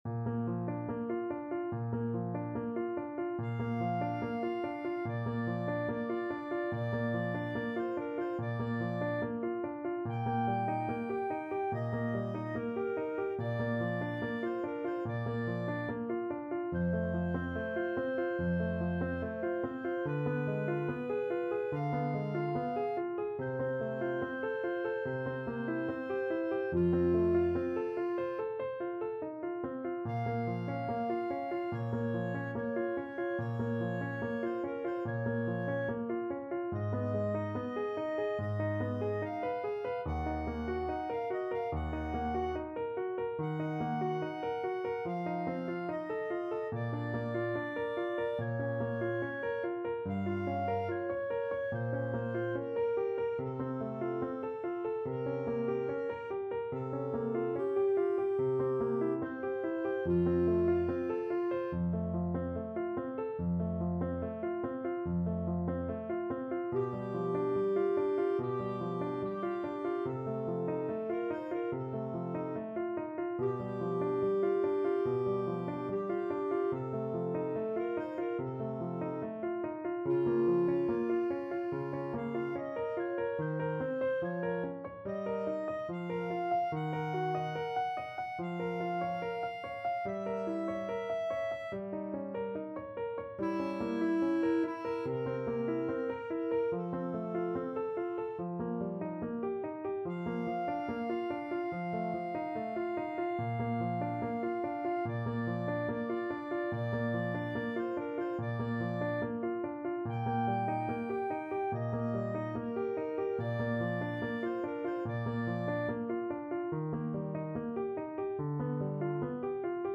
Andante =72
Classical (View more Classical Clarinet Duet Music)